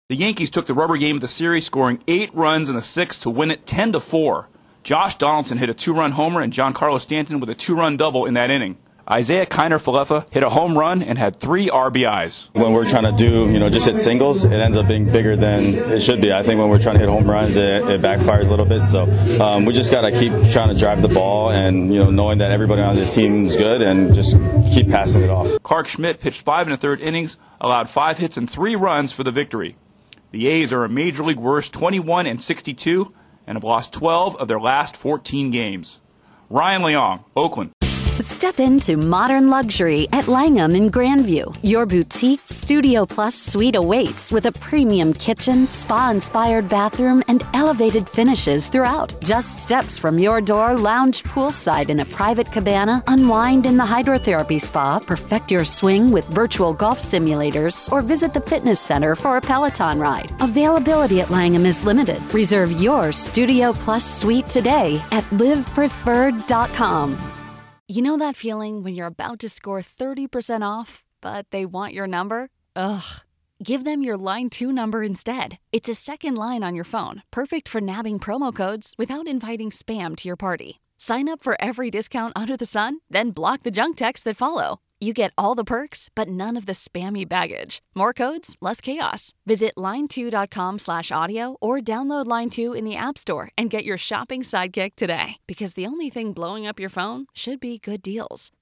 A big inning leads to a lopsided win for the Yankees. Correspondent